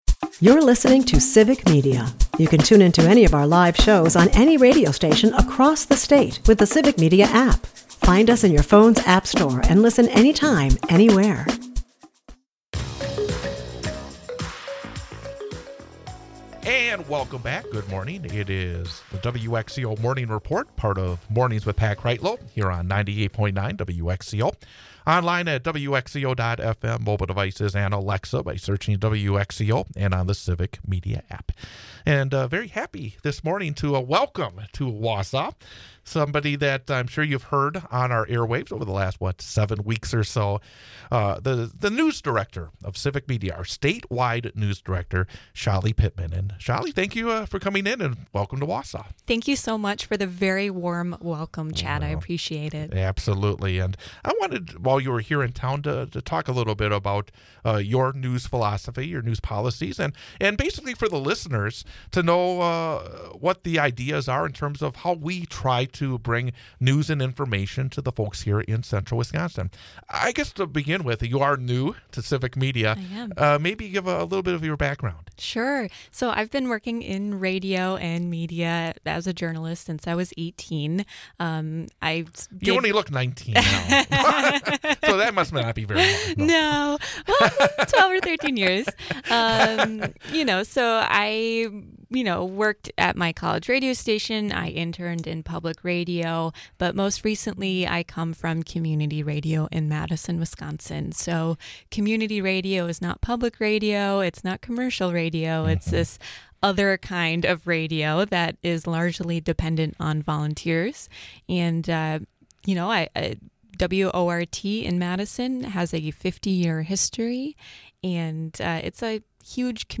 Unedited interview